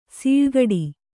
♪ sīḷgaḍi